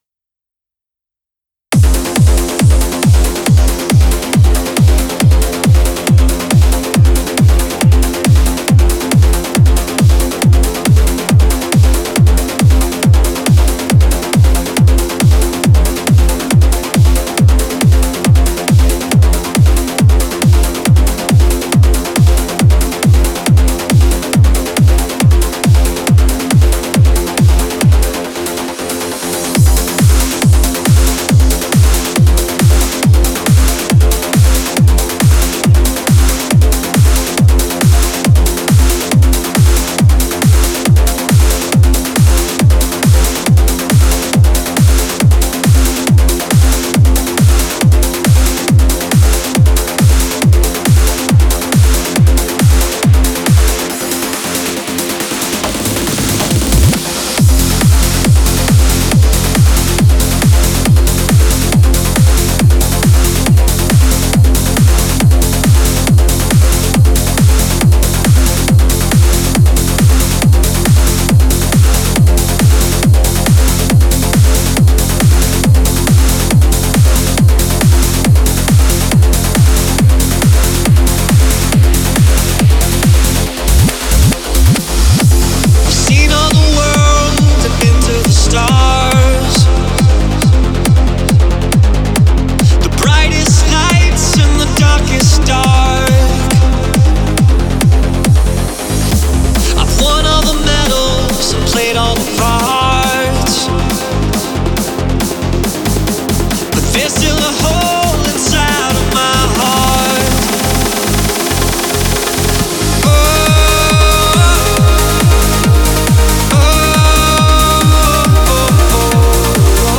Стиль: Vocal Trance / Uplifting Trance